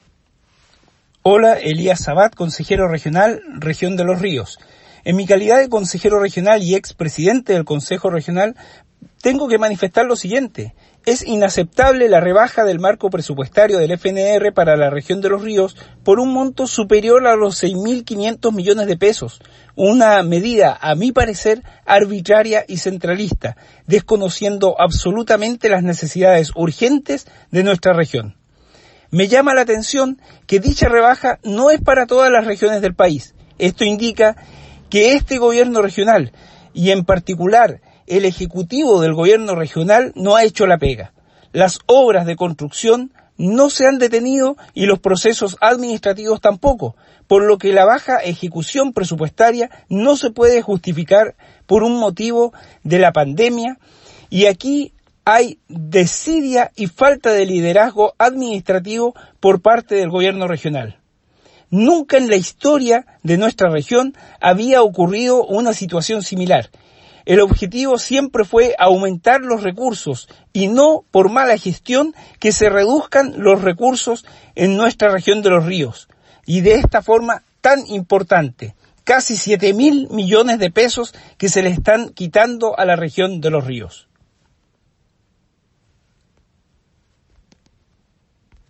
Declaración pública
Declaracion-Publica-Elias-Sabat.mp3